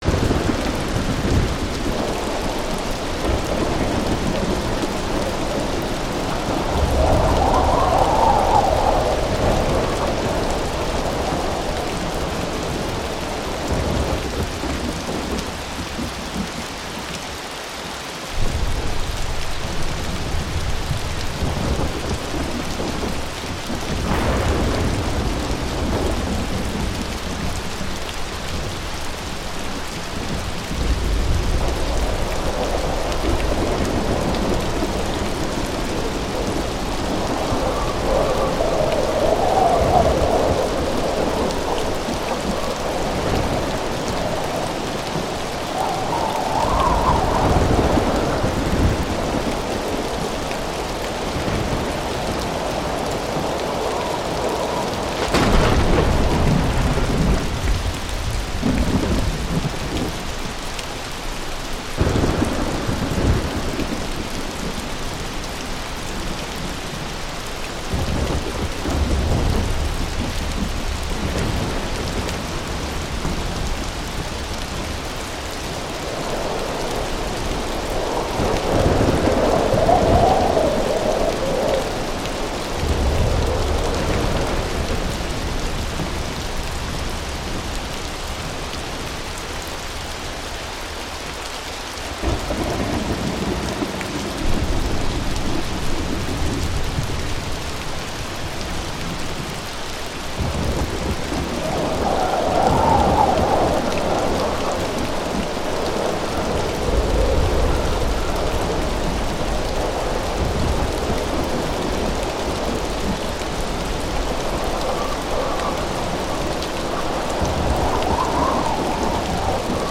KLEINE ENTSPANNENDE KASKADE: Heilquelle-Kaskadentropfen fallen in Kaskade